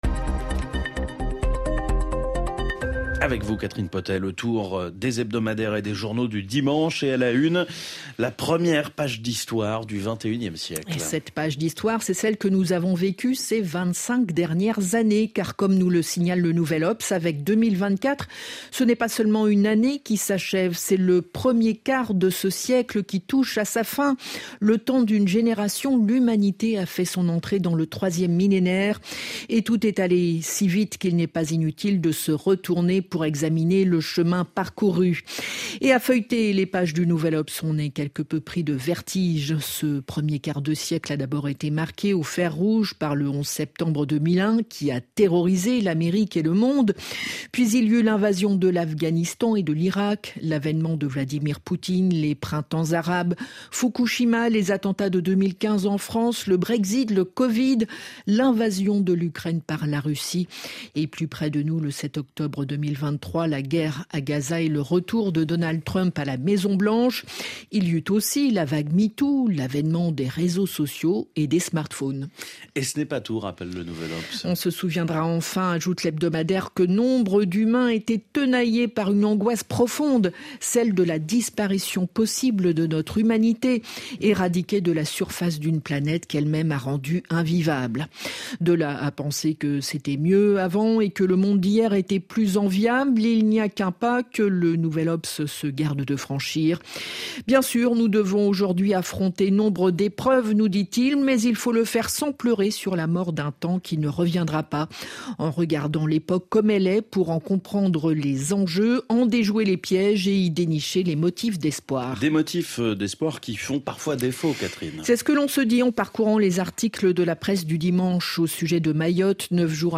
Les opinions, les points de vue et les avis souvent divergents des éditorialistes et des commentateurs sur l'actualité française et internationale dans les hebdomadaires français. Une revue de presse